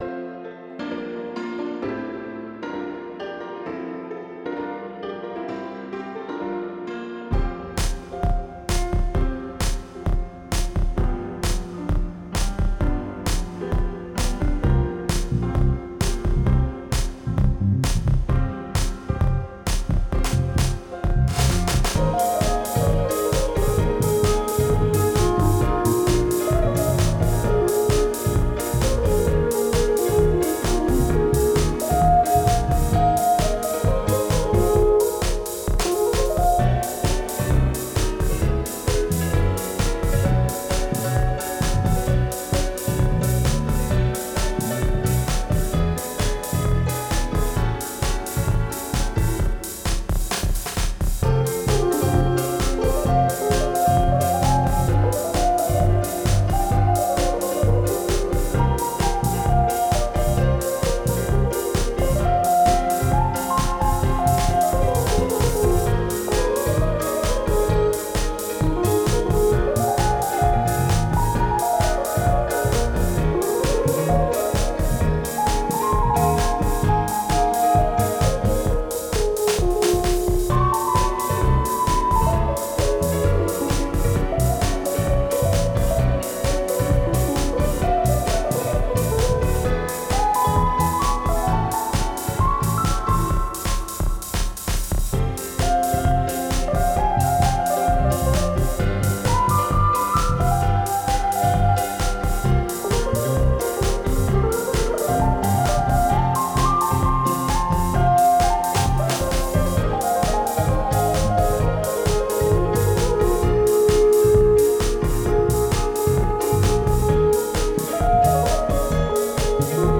Electric Bass, Techno Drums, Acoustic Pianos, Synth.
Genre: Hip-Hop